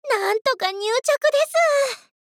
贡献 ） 协议：Copyright，其他分类： 分类:雪之美人语音 您不可以覆盖此文件。